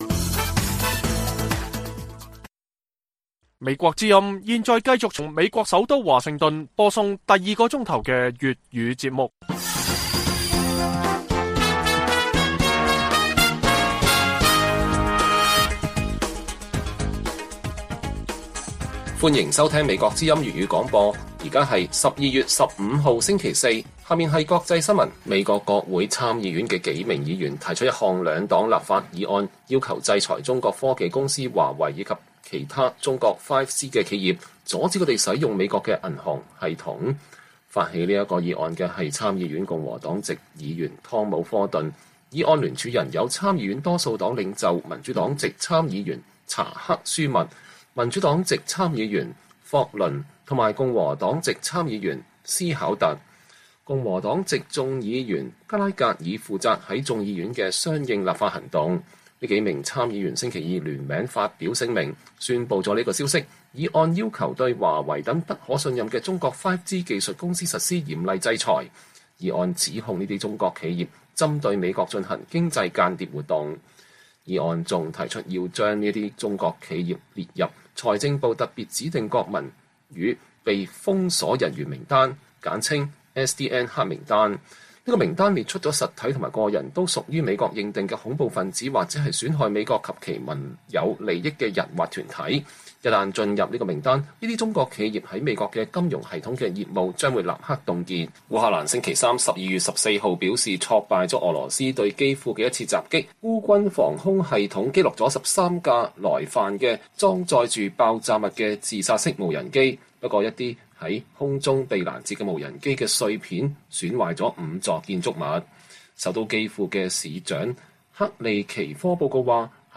粵語新聞 晚上10-11點: 美國參議院提出議案要將華為等中國企業剔出美國金融系統